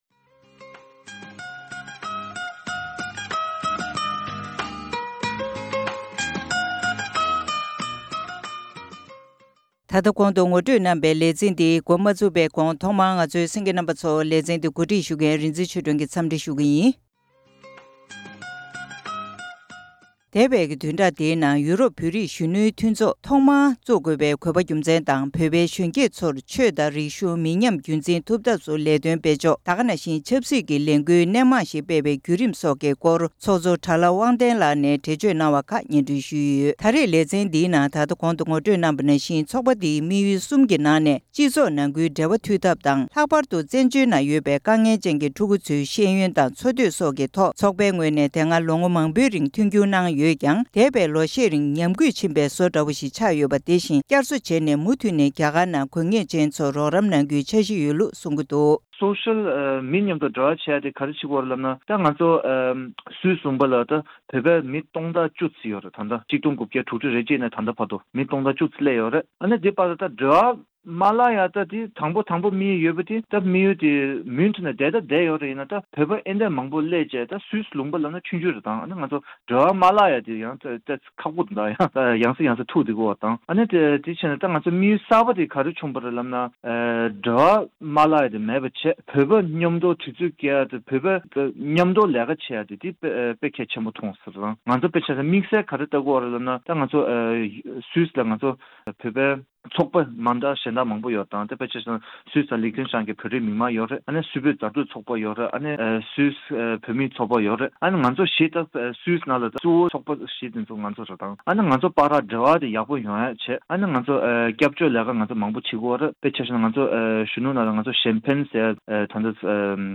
ཡོ་རོབ་བོད་རིགས་གཞོན་ནུའི་མཐུན་ཚོགས། ལེ་ཚན་གཉིས་པ། སྒྲ་ལྡན་གསར་འགྱུར།